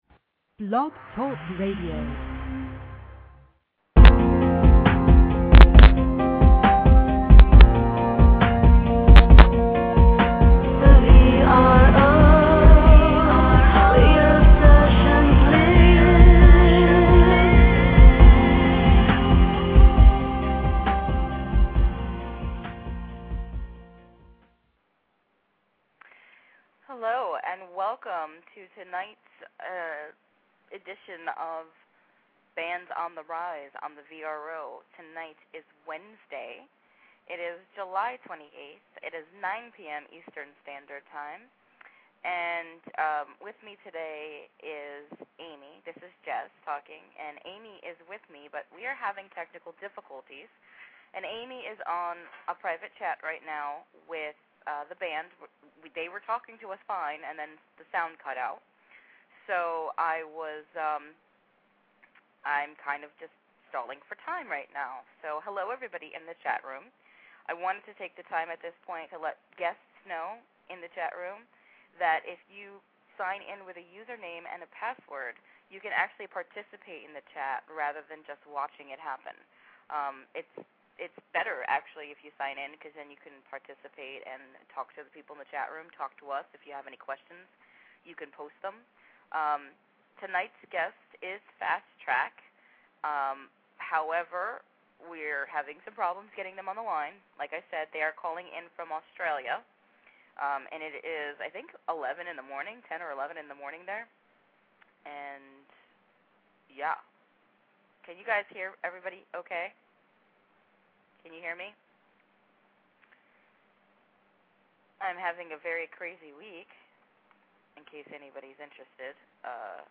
Interviews / Fastrack Interviews